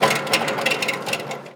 metal_rattle_spin_small_01.wav